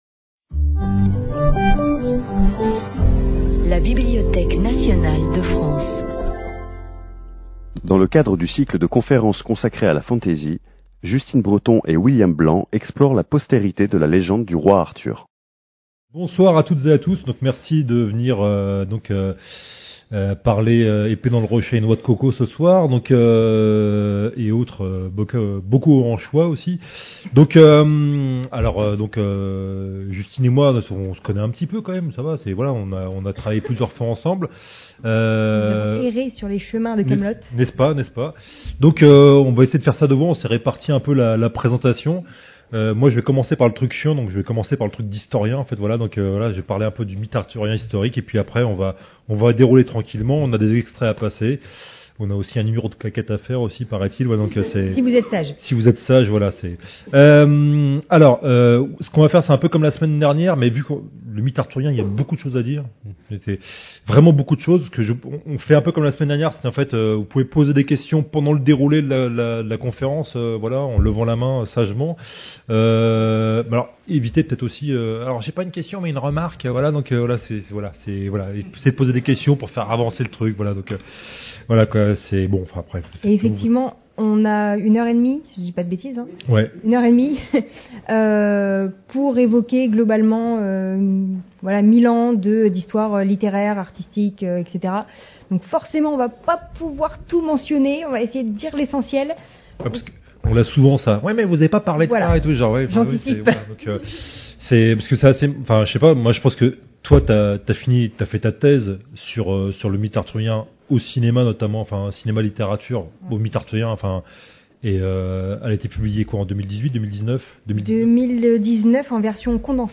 conference-roi-arthur-medievalisme.mp3